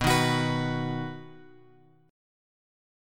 B chord {7 6 x x 7 7} chord
B-Major-B-7,6,x,x,7,7.m4a